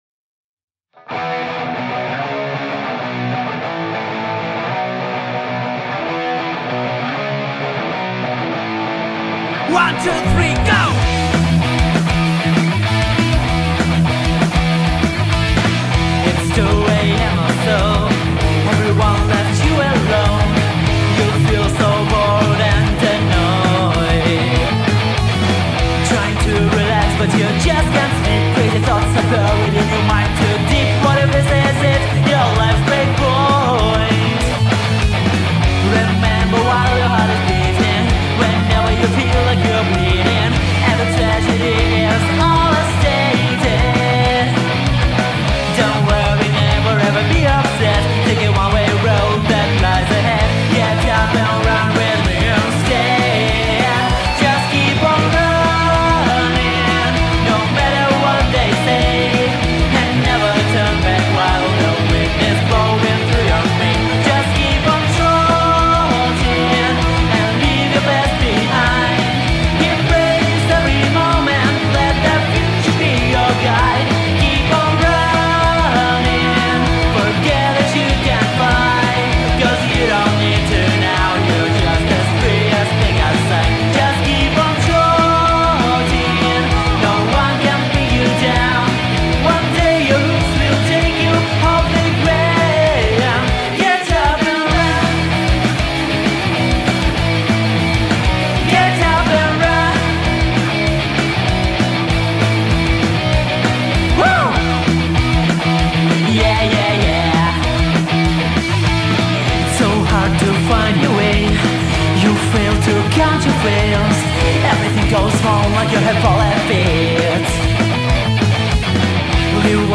Flute
Bass